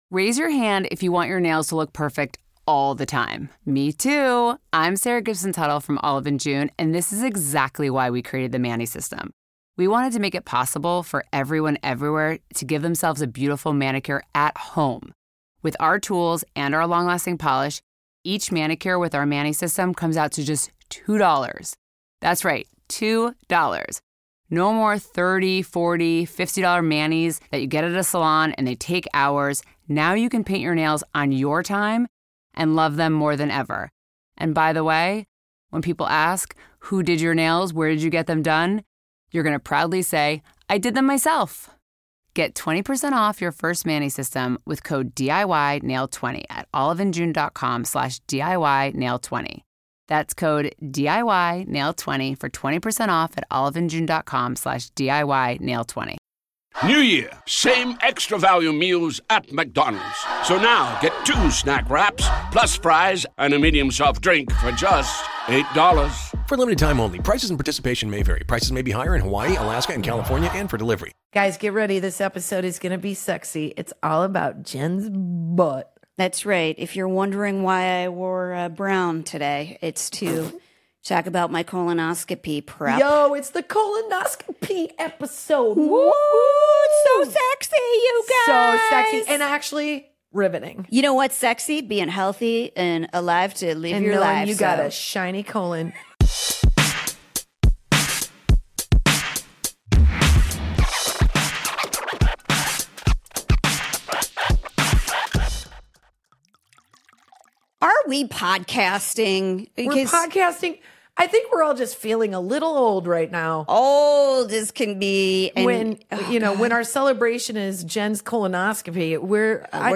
Female comedy duo